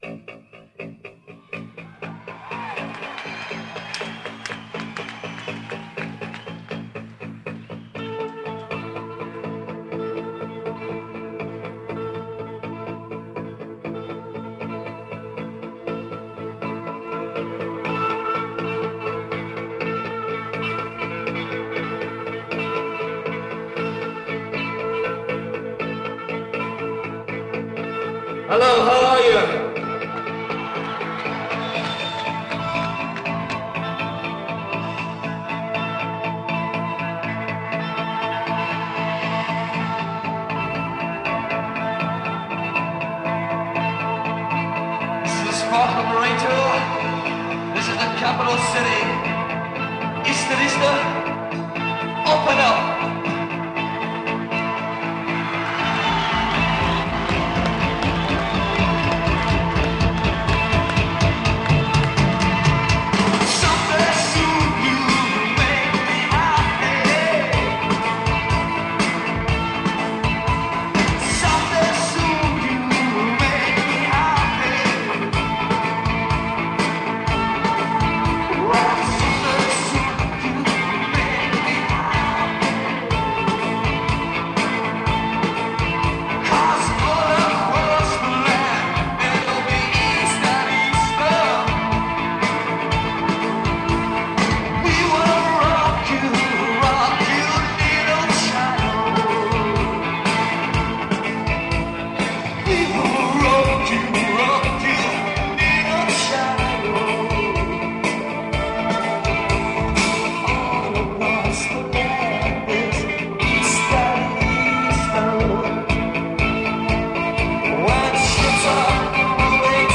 In Concert – Hammersmith Odeon, May 15, 1984
Helped put an indelible stamp on 80s Rock